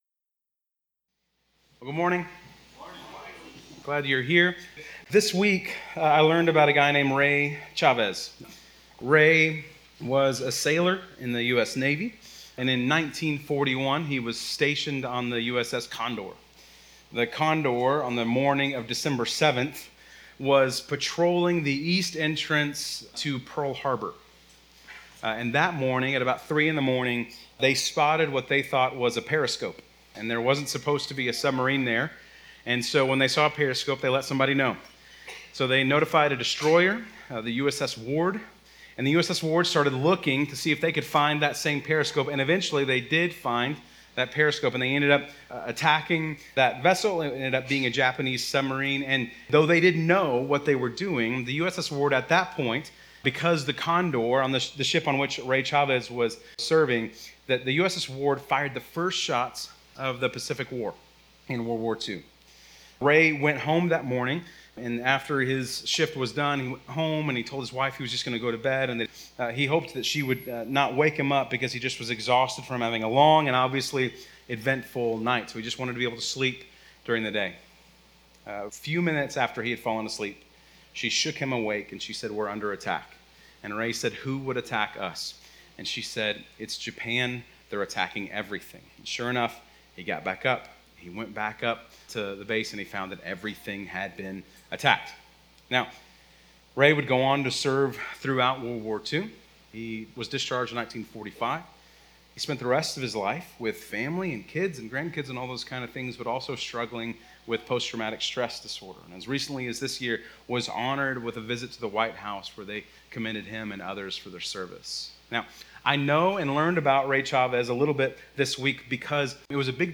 Bible Text: Matthew 4:17-25 | Preacher